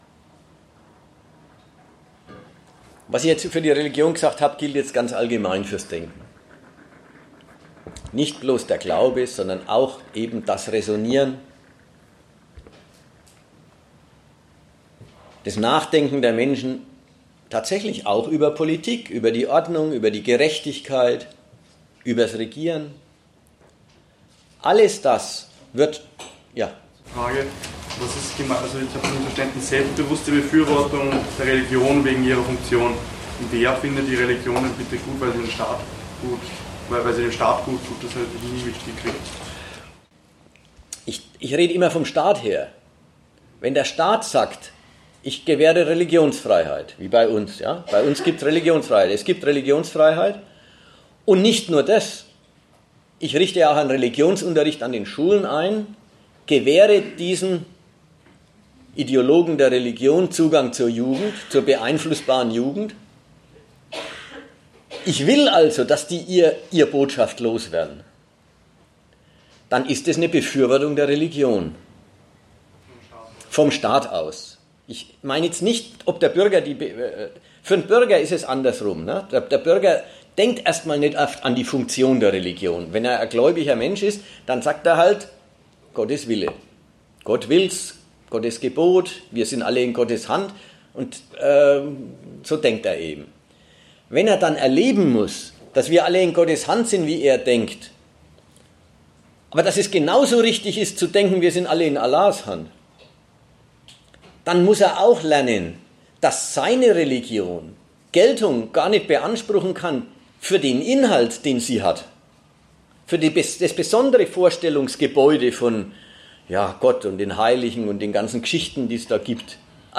Die Gründe dafür – neben noch anderen schlechten Nachrichten von diesem edlen Wert – bietet der Vortrag mit Redakteuren der Zeitschrift GegenStandpunkt.